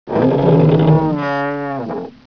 bigcreak.wav